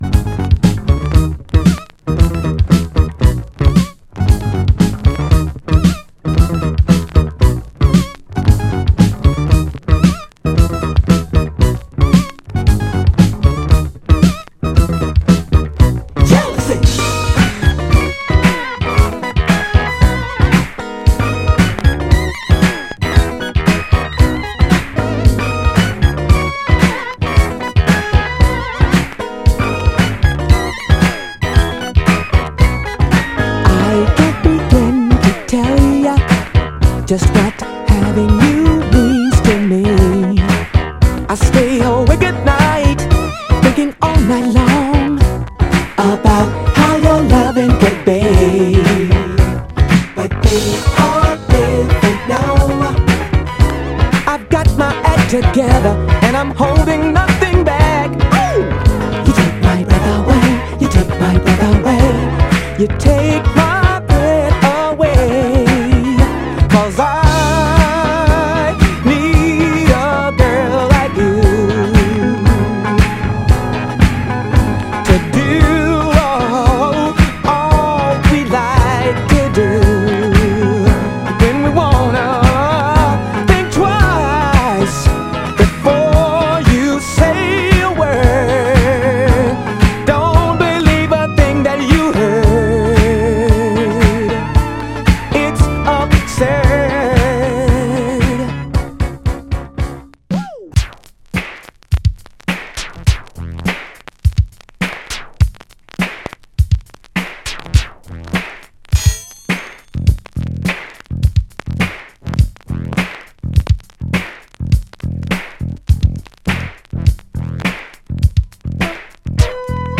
両面共に序盤以降は大きく目立つノイズは少なく、グロスが残っておりDJプレイは可能な範囲内かと。
FORMAT 7"
※試聴音源は実際にお送りする商品から録音したものです※